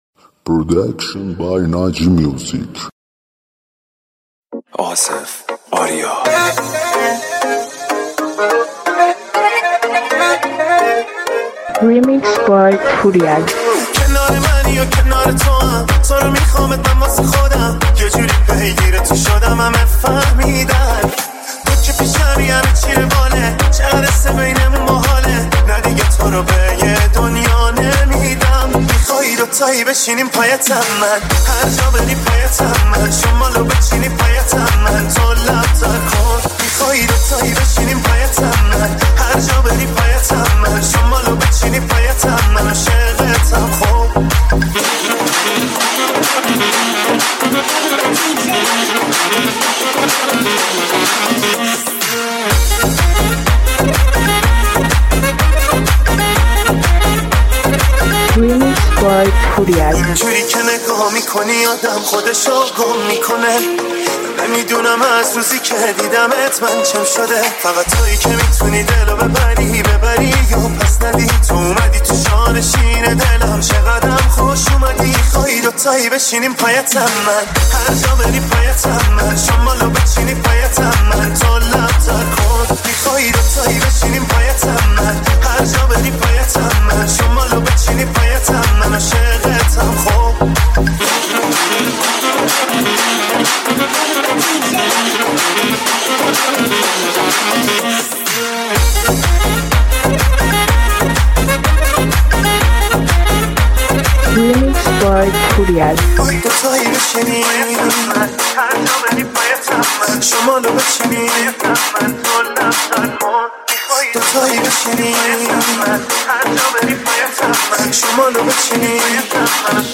ریمیکس شاد تریبال مخصوص باشگاه
ریمیکس شاد تریبال مخصوص رقص